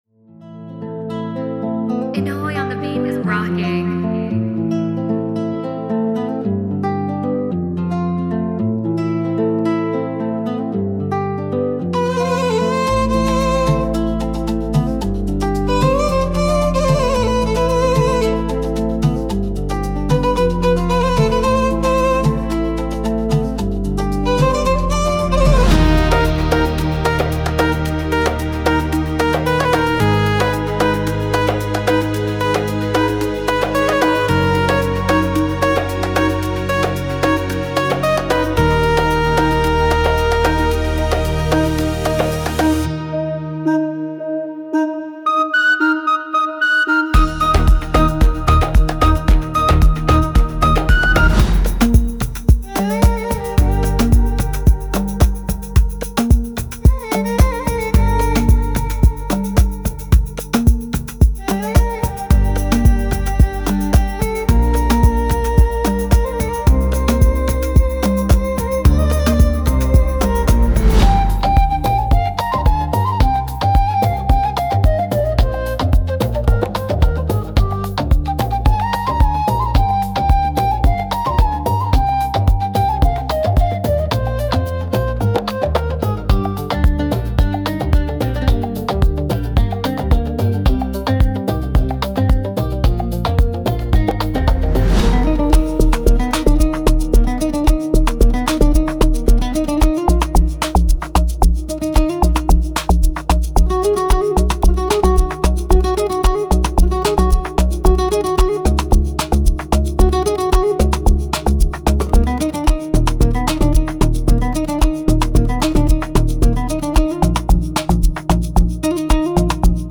• 199 Sample Loops (Strings, Flutes, Drums & Synths)